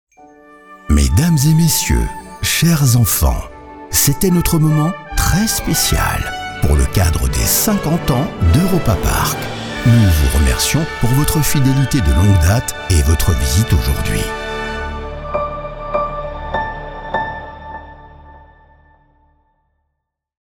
Französischer Werbesprecher
SONORE, ANGENEHME STIMME
A RESONANT, PLEASANT VOICE